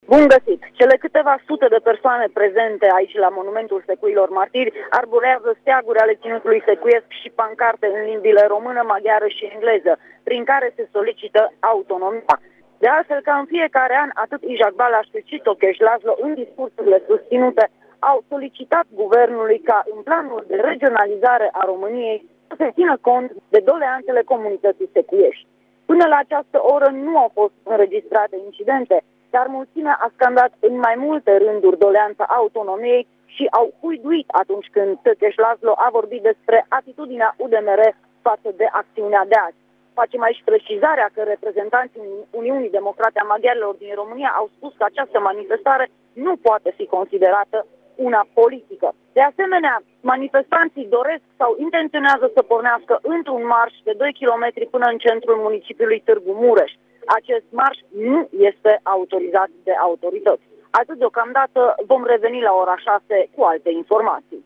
Este la Monumentul Secuilor Martiri și vine cu detalii